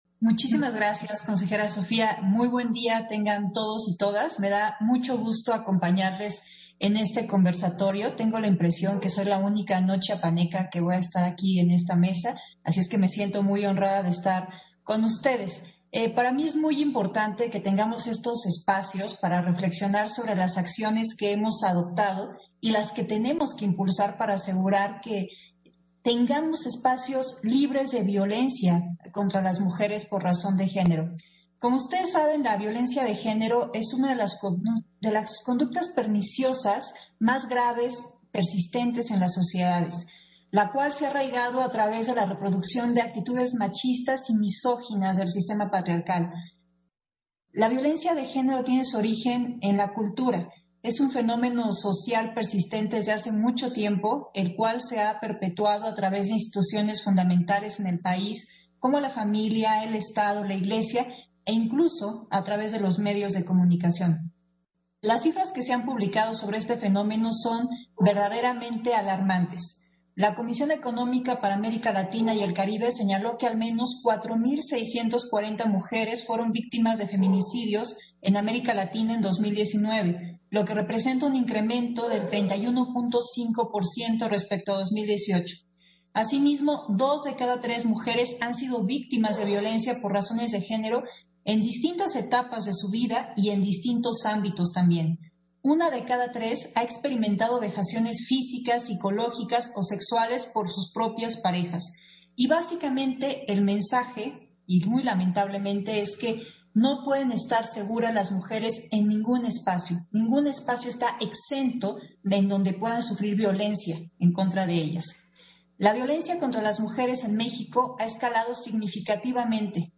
Intervención de Dania Ravel, en el 4º Conversatorio virtual: Mujeres al poder libres de violencia política en razón de género